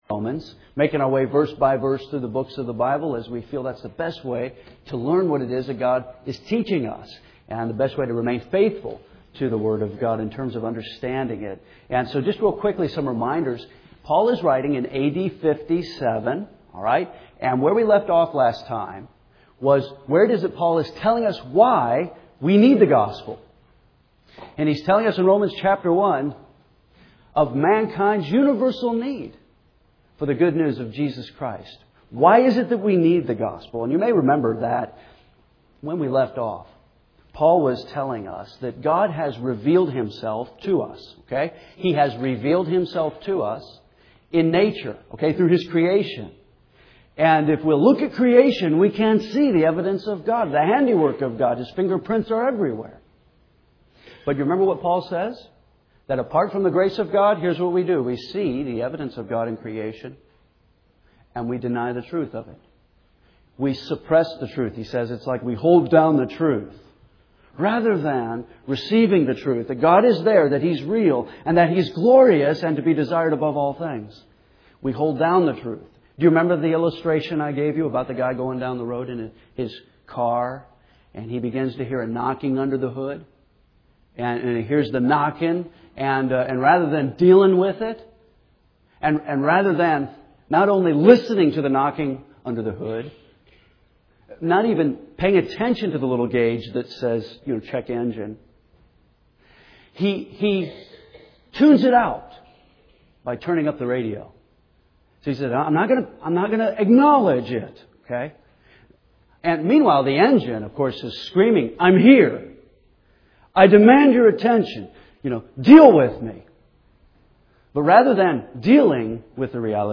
First Baptist Church Henderson, KY